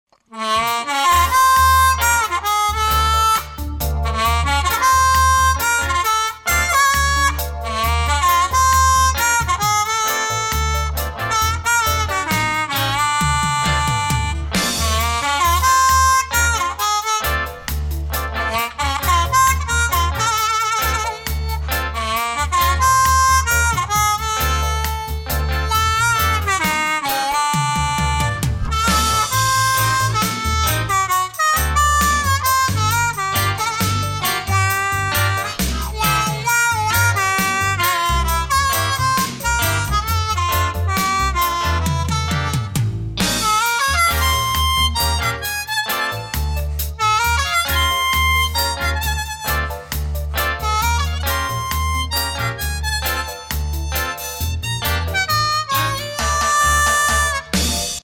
Whole-Tone test driving area